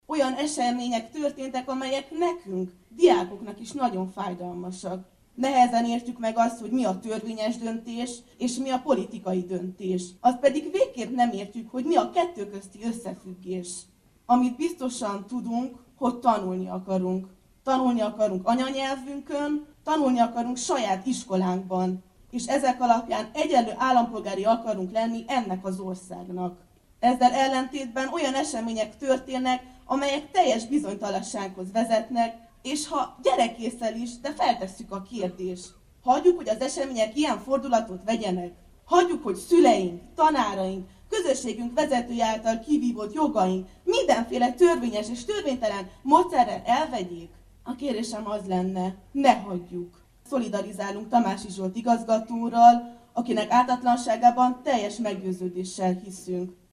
Ezrek szavalták Reményik Sándor versét a Vársétányon